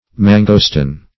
Mangosteen \Man"go*steen\, Mangostan \Man"go*stan\, mangosteen